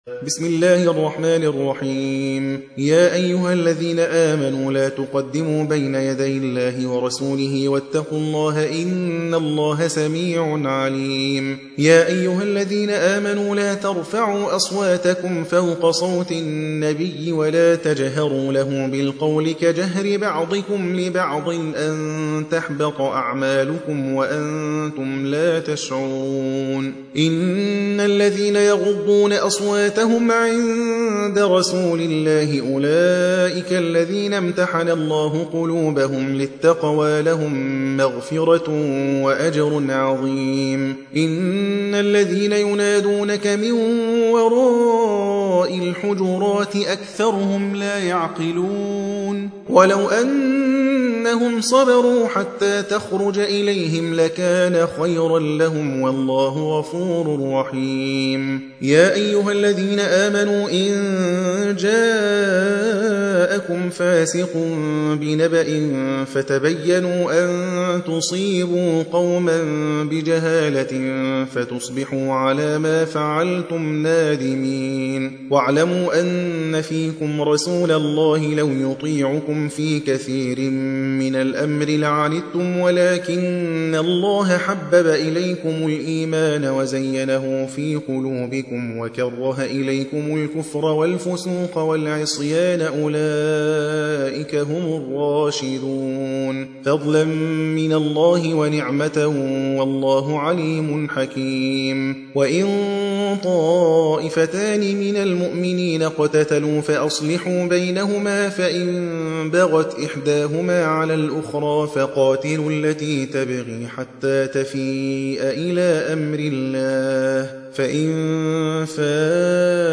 49. سورة الحجرات / القارئ